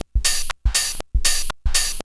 B1HOUSE123.wav